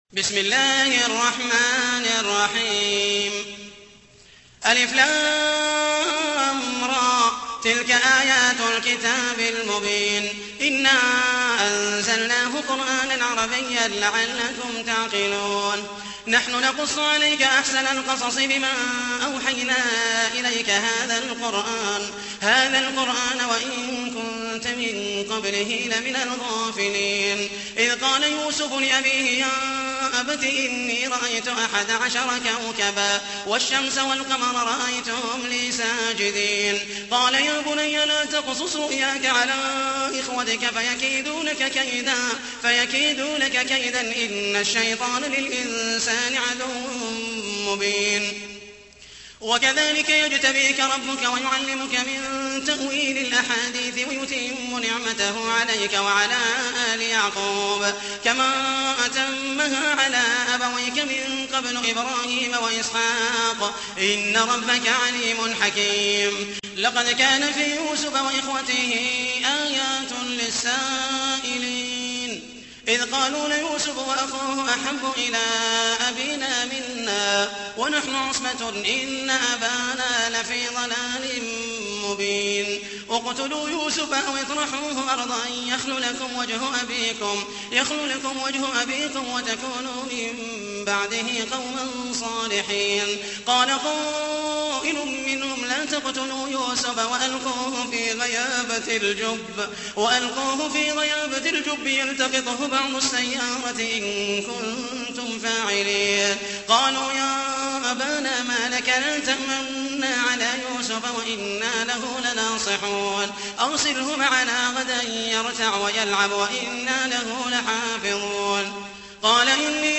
تحميل : 12. سورة يوسف / القارئ محمد المحيسني / القرآن الكريم / موقع يا حسين